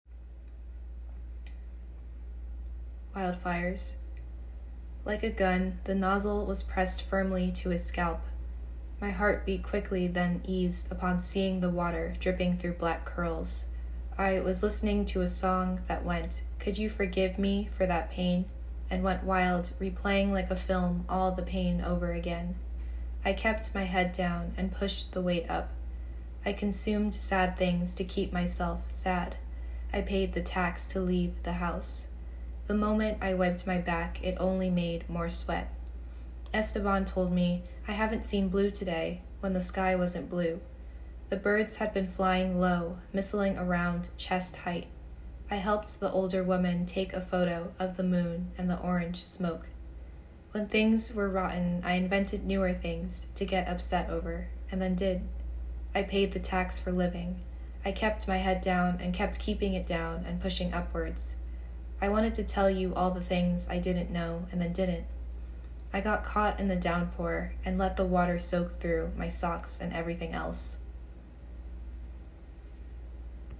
Wildfires-reading.m4a